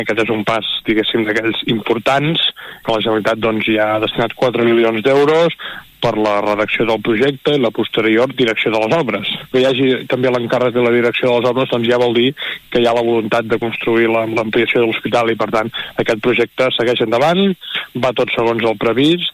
L’alcalde Marc Buch ha dit a Ràdio Calella TV que la licitació del projecte és un pas molt important en el procés que ha de culminar amb l’entrada en funcionament del nou hospital.